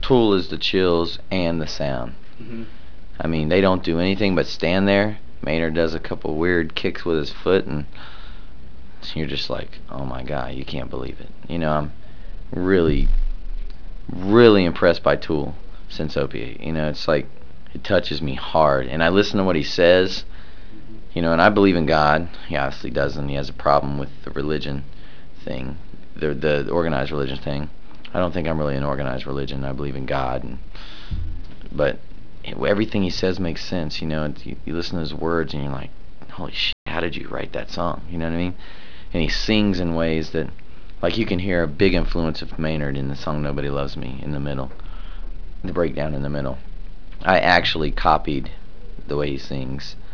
[Listen to Fred speak - 250k Audio]
fredlimp.au